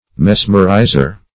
Mesmerizer \Mes"mer*i`zer\, n.